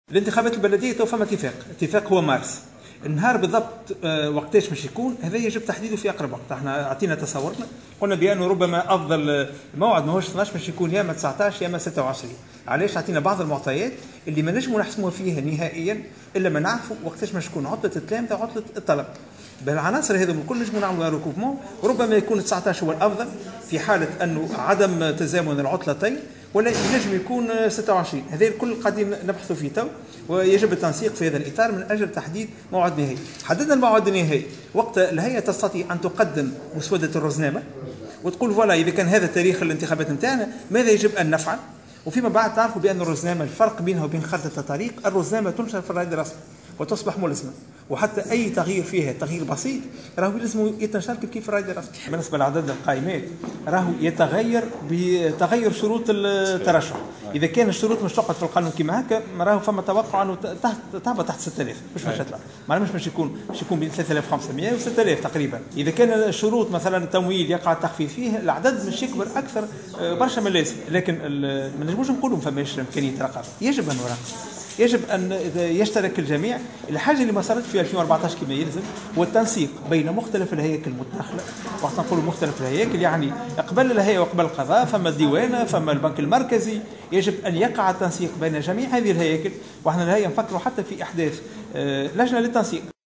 أكد رئيس الهيئة العليا المستقلة للإنتخابات شفيق صرصار على هامش ورشة عمل حول...